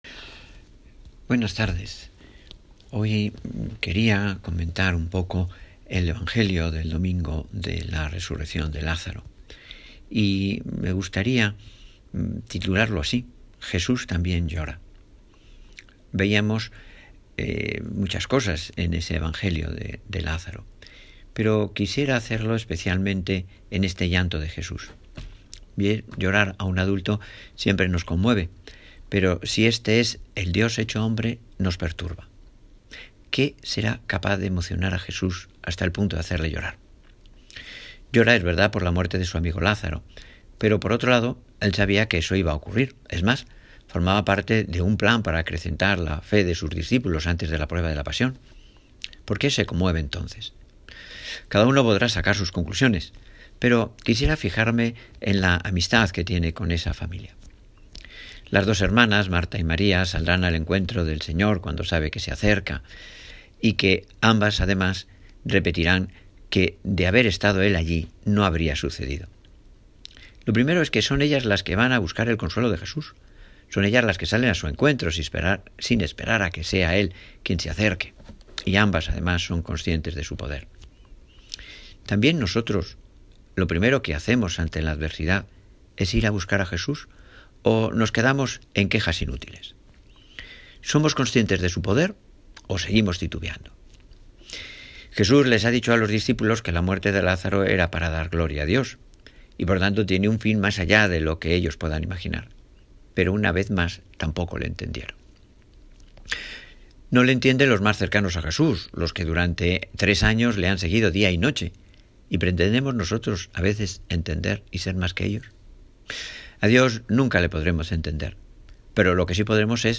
Bajo este título, nuestro Párroco reflexiona sobre el Evangelio del domingo pasado, que trata de la resurrección de Lázaro, una de las 3 ocasiones en las que la Biblia recoge que Jesús llora.